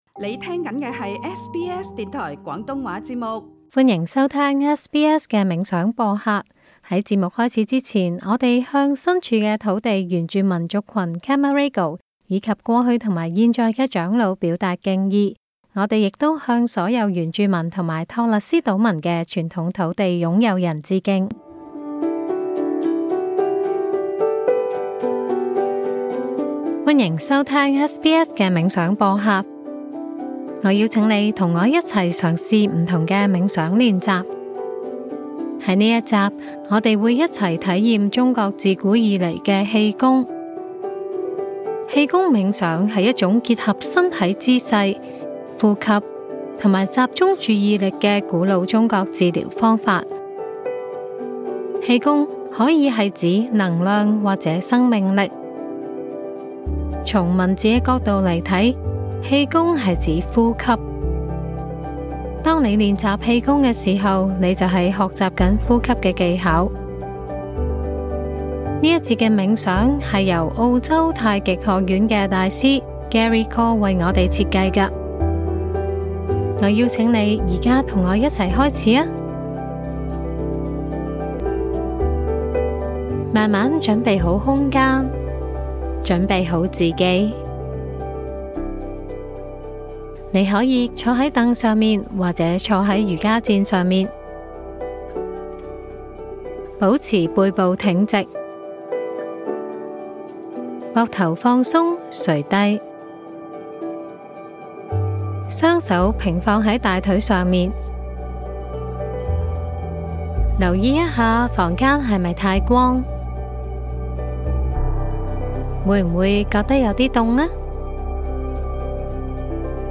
氣功冥想是一種源自中國古代，結合輕柔運動和調整呼吸的傳統養生方法。這一集我們會體驗基本的正念冥想，請你先找一個舒適的地方坐在椅子或墊子上。